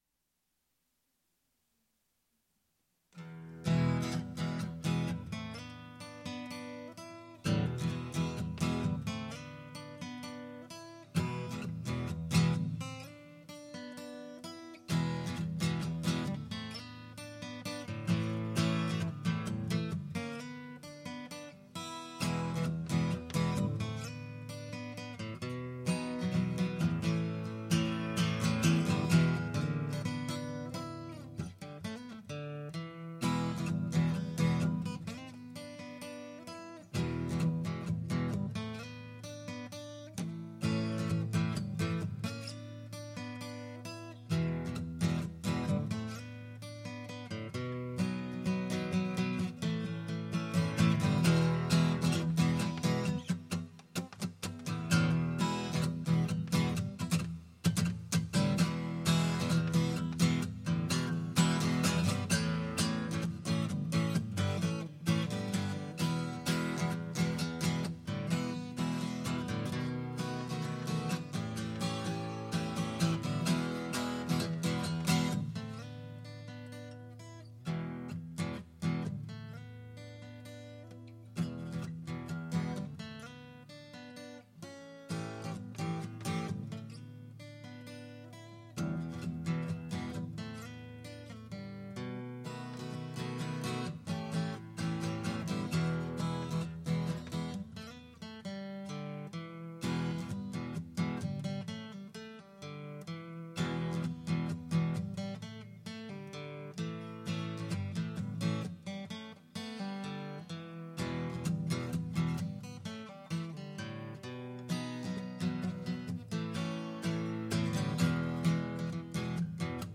Messing around with some acoustic guitar blues. Much better recording.